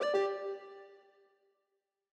Longhorn Valley - Hardware Remove.wav